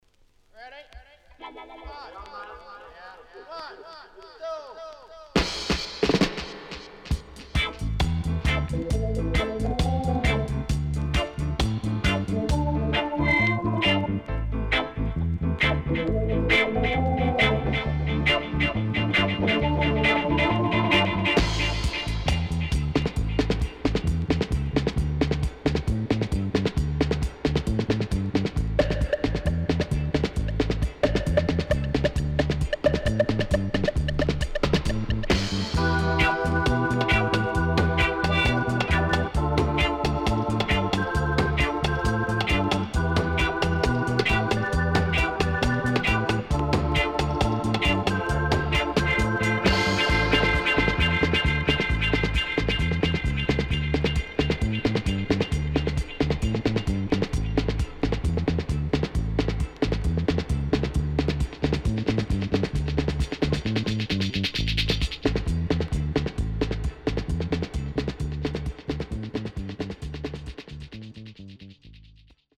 HOME > REGGAE / ROOTS
SIDE A:所々チリノイズがあり、少しプチノイズ入ります。